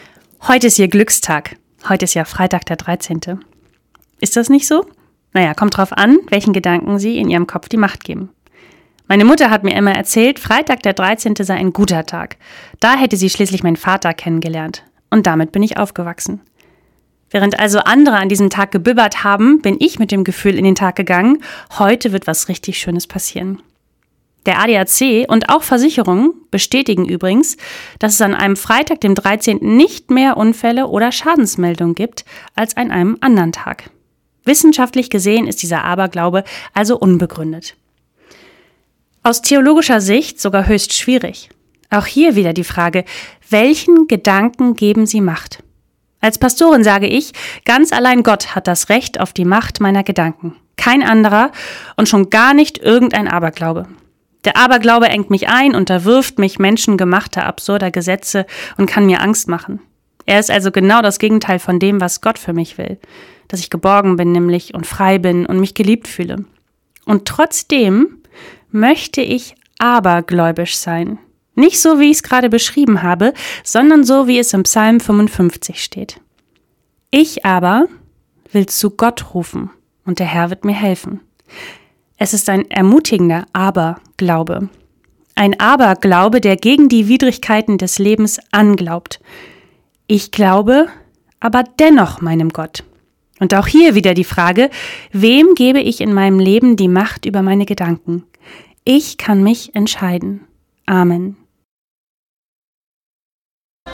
Weserbergland: Radioandacht vom 13. März 2026